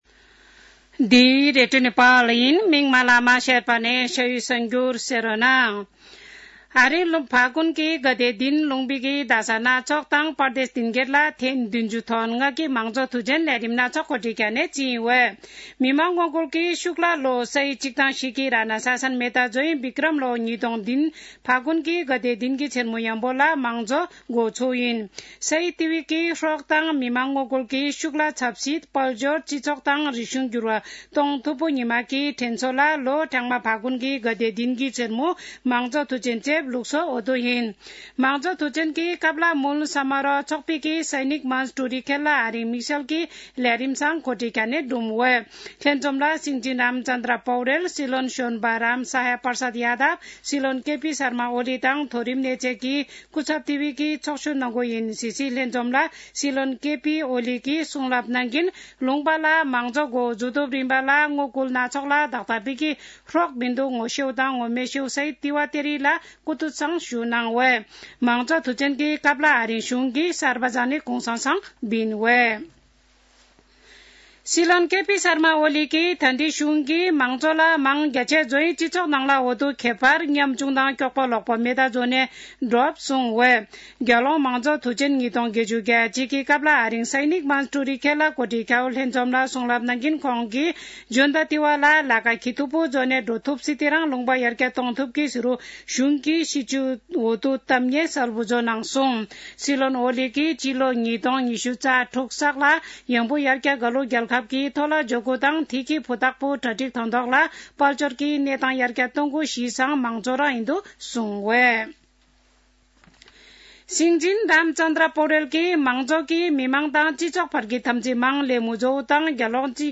शेर्पा भाषाको समाचार : ८ फागुन , २०८१
Sherpa-News-11-07.mp3